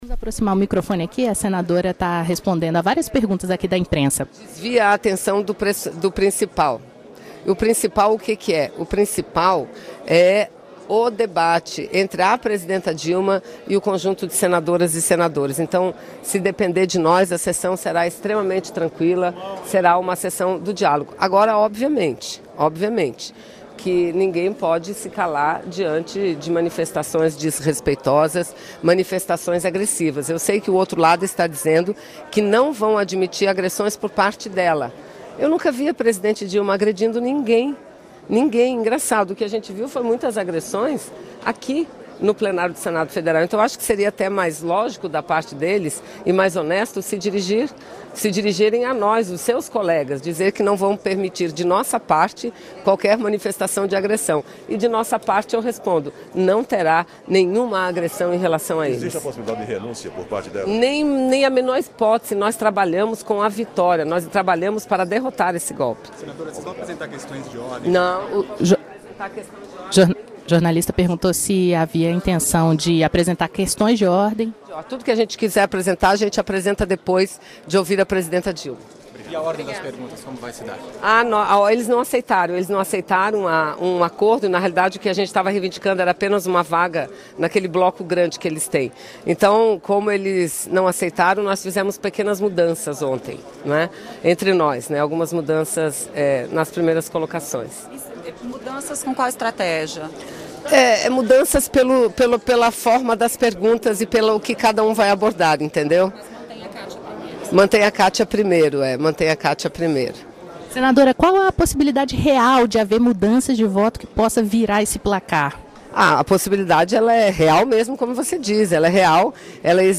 Em entrevista a jornalistas, a senadora afirmou que a sessão desta segunda-feira (29) será uma sessão de diálogo, sem manifestações agressivas por parte de Dilma. Vanessa Grazziotin afirmou que a possibilidade de mudar o placar do julgamento é real.